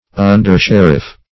Search Result for " undersheriff" : The Collaborative International Dictionary of English v.0.48: Undersheriff \Un"der*sher`iff\, n. A sheriff's deputy.